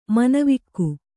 ♪ manavikku